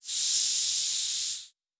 Air Hiss 1_03.wav